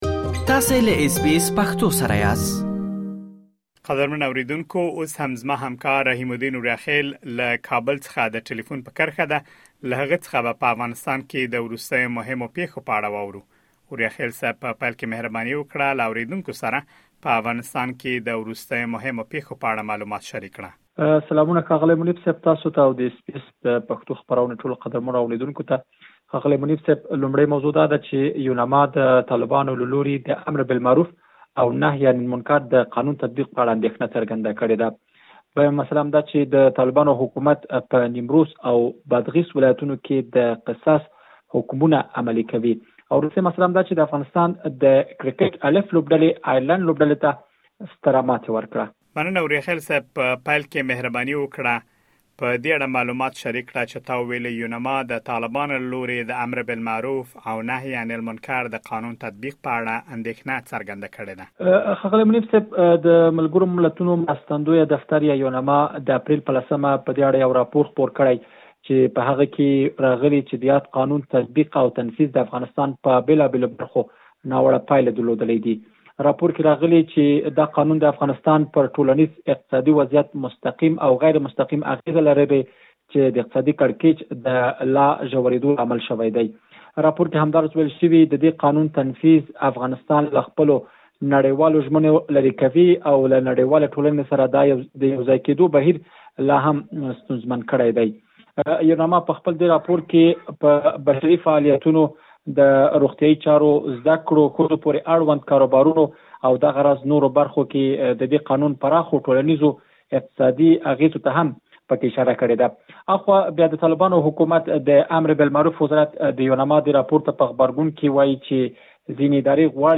د افغانستان د وروستیو پېښو په اړه مهم معلومات په ترسره شوې مرکې کې اورېدلی شئ.